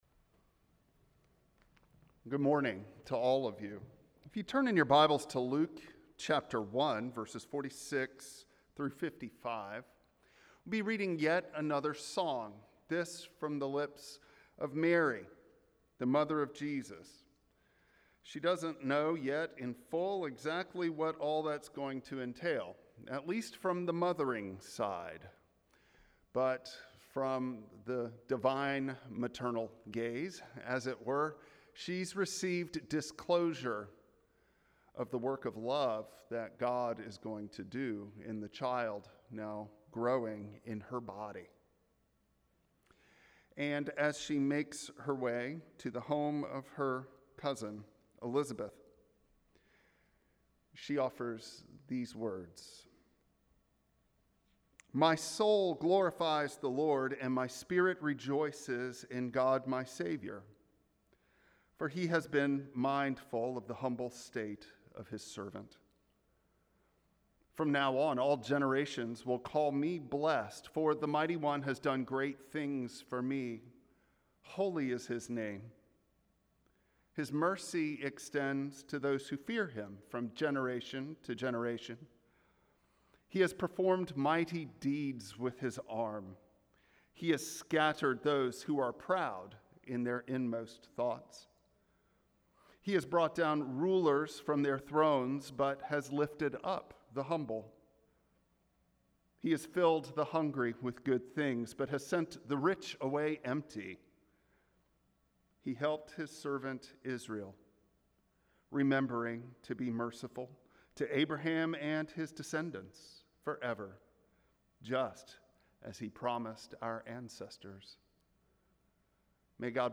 Removed intermittent audio errors and popping.
Luke 1:46-55 Service Type: Traditional Service Bible Text